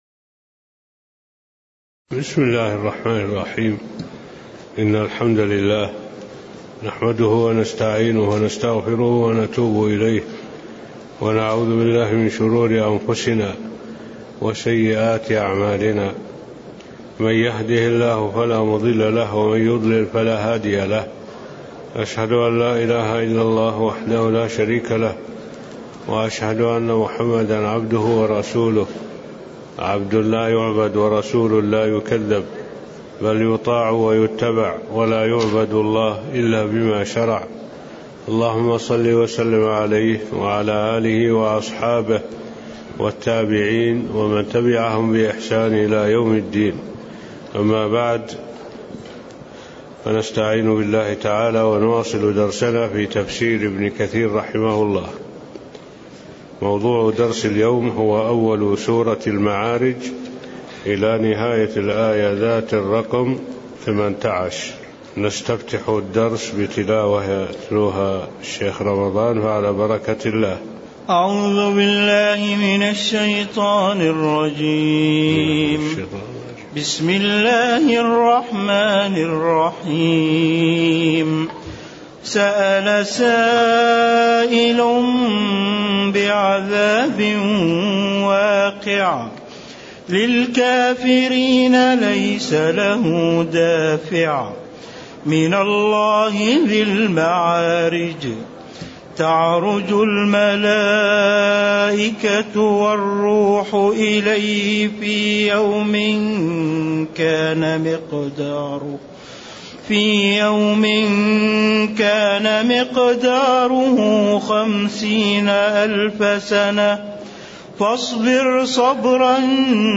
المكان: المسجد النبوي